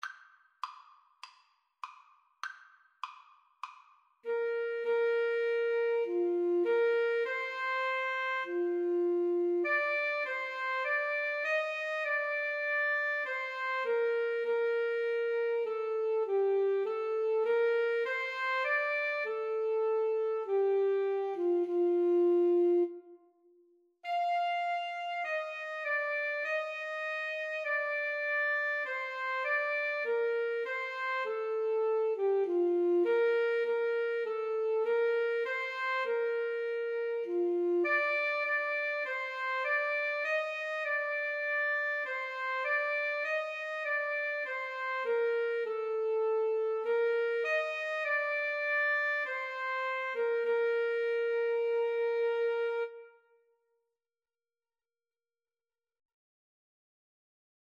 Alto SaxophoneTrumpetAlto Saxophone (8vb)
4/4 (View more 4/4 Music)